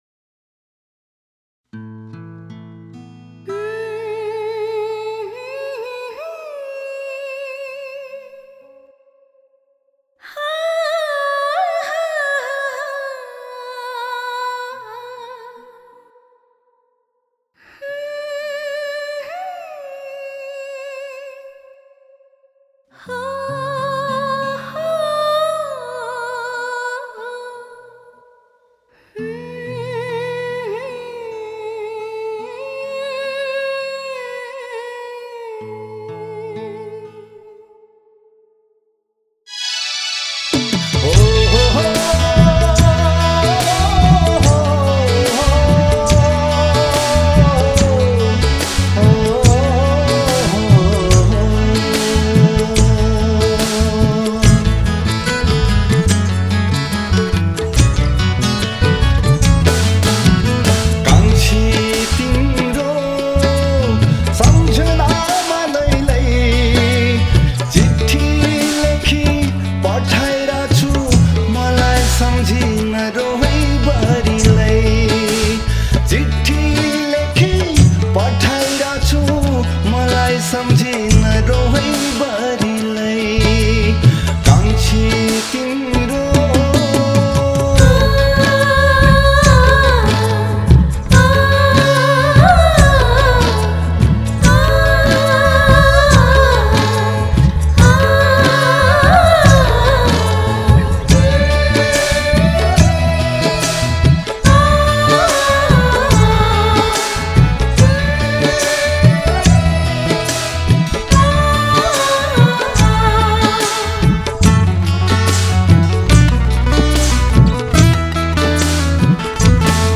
Tracks include: 1. Tabla 2. Dholak 3. Digital Drum 3. Shakers 5. Duff 6. 2 guitars 7. Bass 8. Sarangi 9. Key- Strings 10. Male vocal 11. Female harmony vocal I am lost and I dont know how this mix sound.